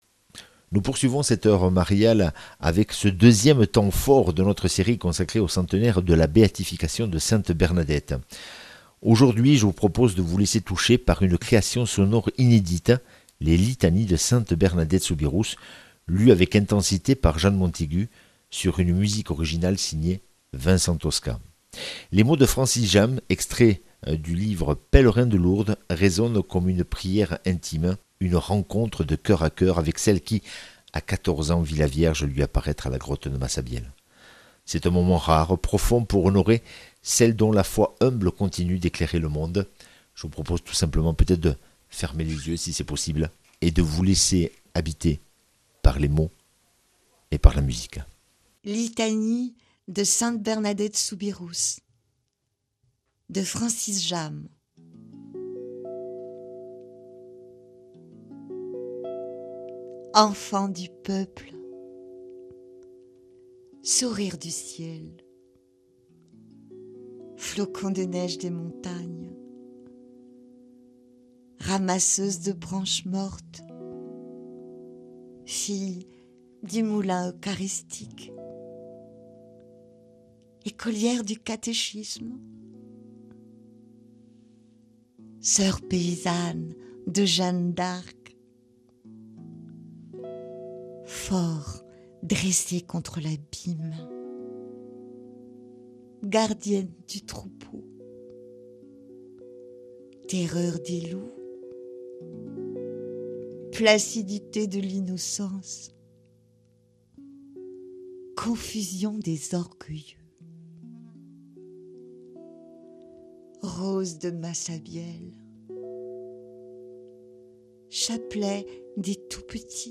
lues avec intensité
sur une musique originale